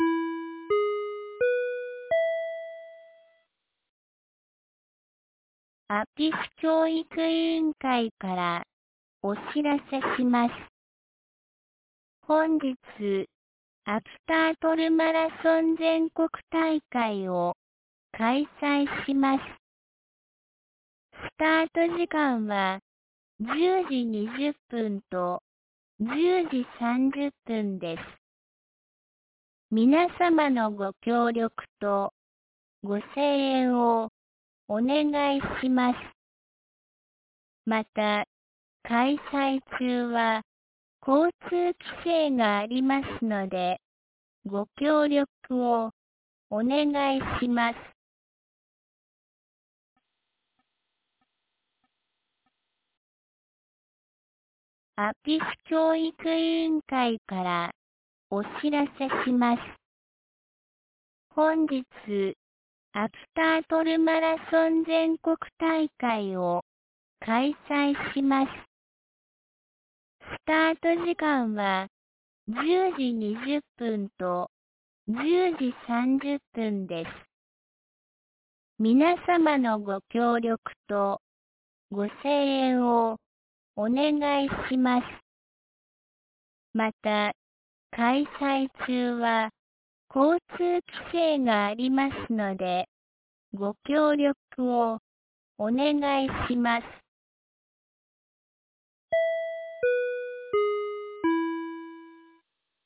2023年12月10日 08時31分に、安芸市より全地区へ放送がありました。